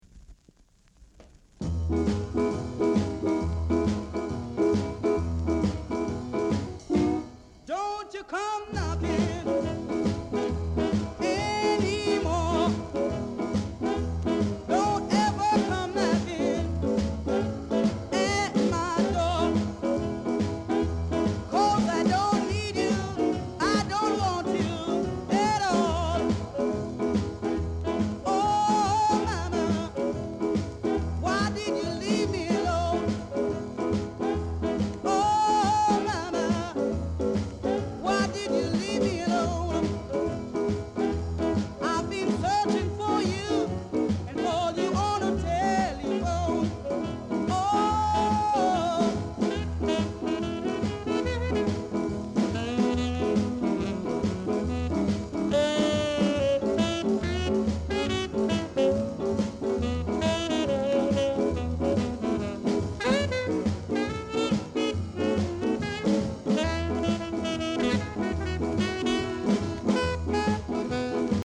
Ska
Ska Male Vocal